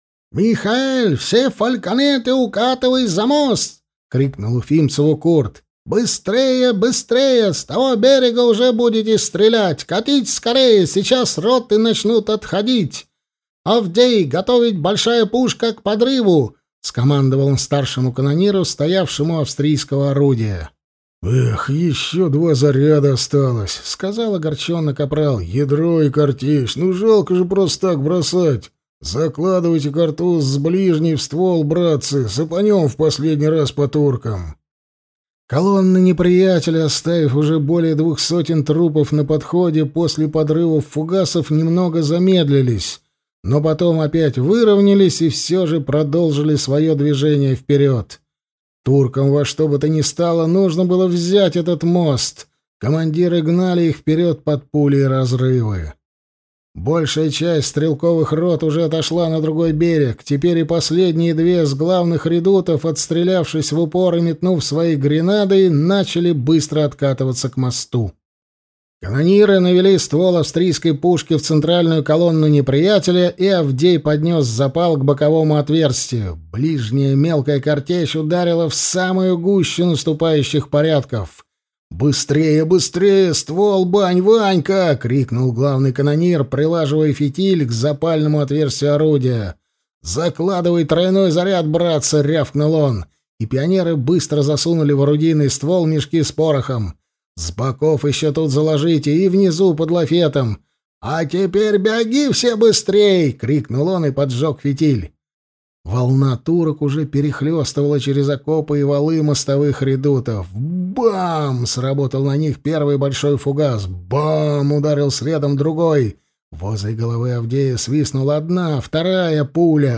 Аудиокнига Егерь императрицы. Гром победы, раздавайся!